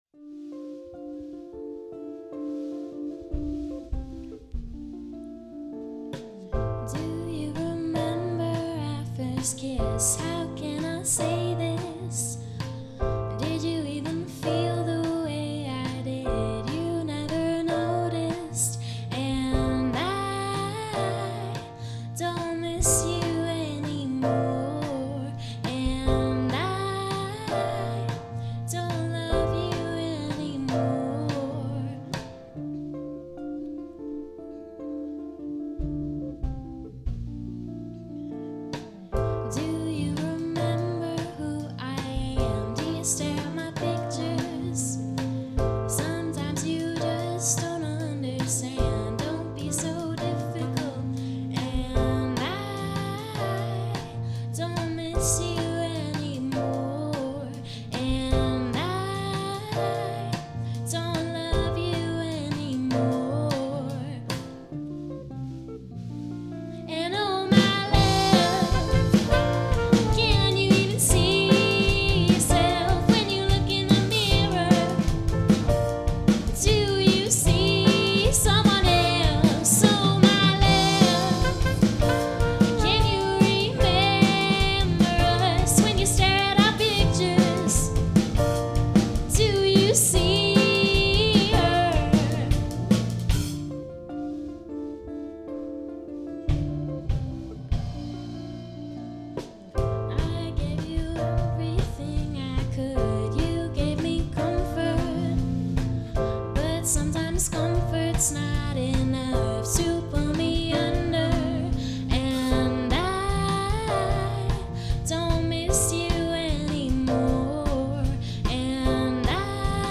Each of the acts recorded live versions of their entries.
unique blend of funk and indie sounds
vocals
saxophone